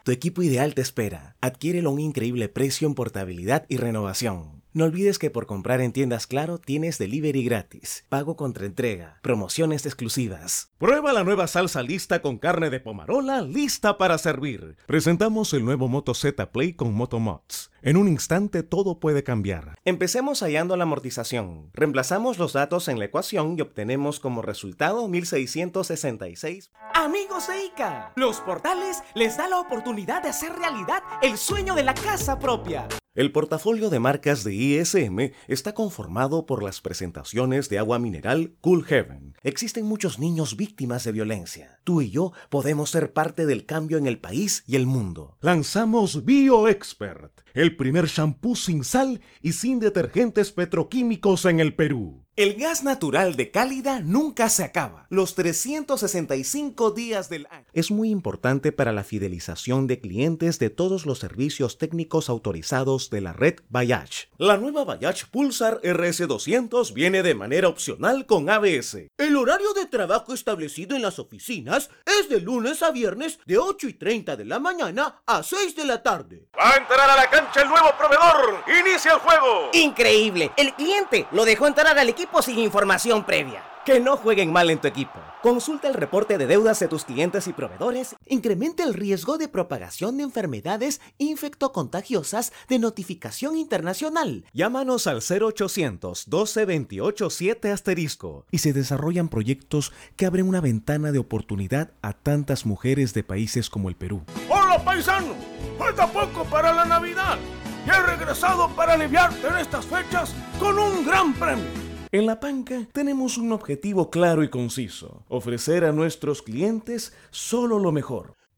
LOCUTOR VOICE OVER ACTOR
Sprechprobe: Werbung (Muttersprache):